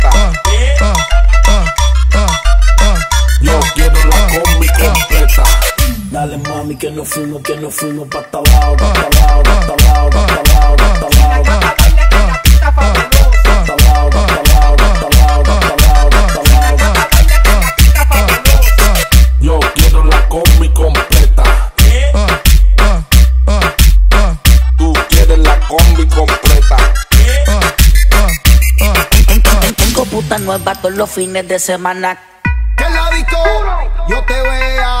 Страстные ритмы латино
Urbano latino
Жанр: Латино